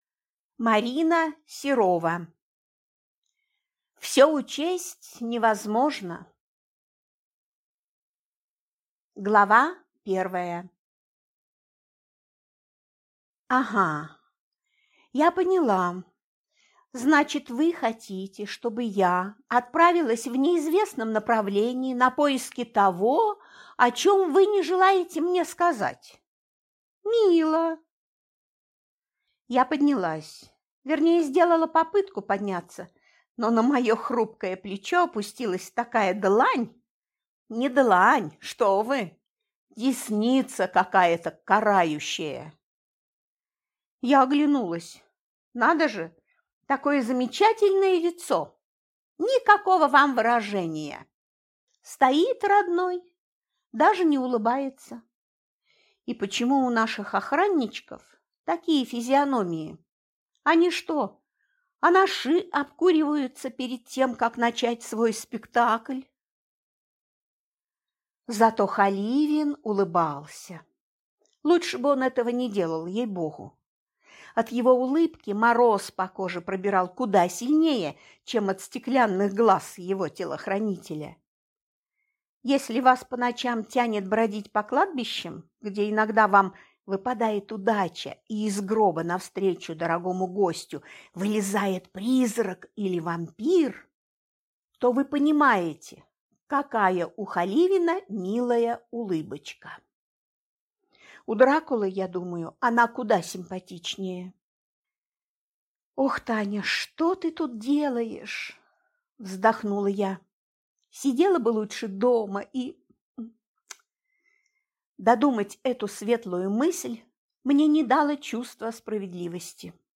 Аудиокнига Все учесть невозможно | Библиотека аудиокниг